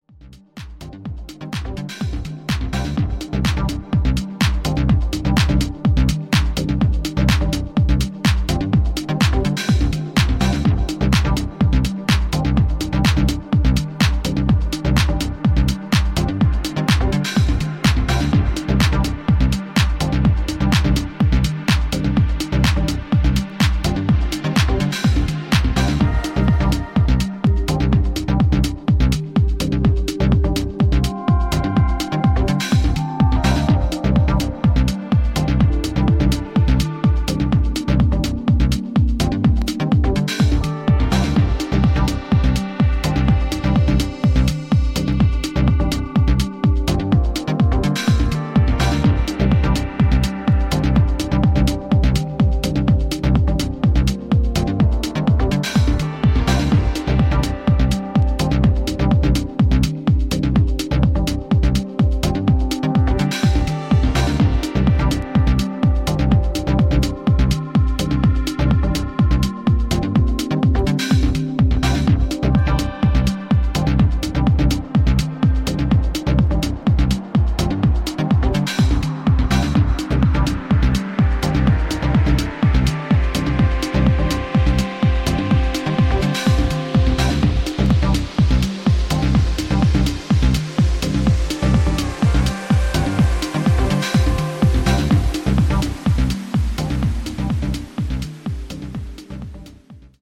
ジャンル(スタイル) DEEP HOUSE / DETROIT